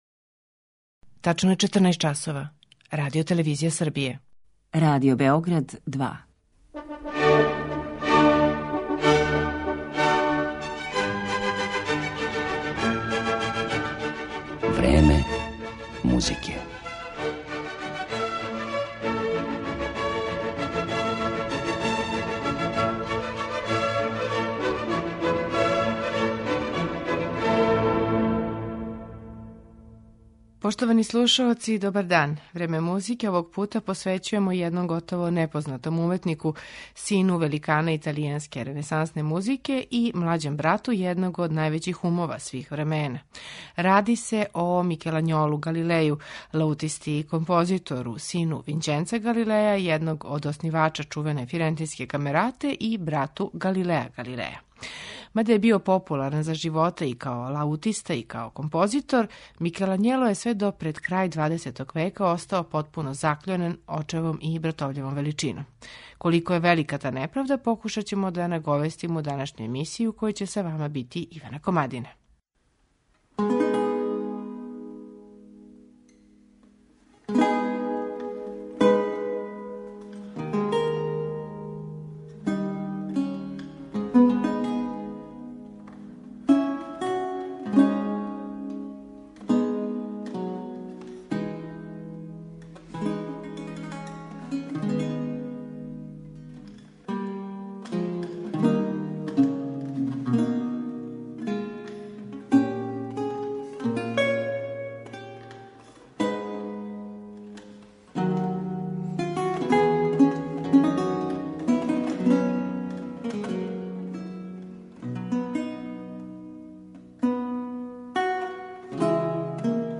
Микелањоло Галилеј - композиције за лауту
уз његове композиције за лауту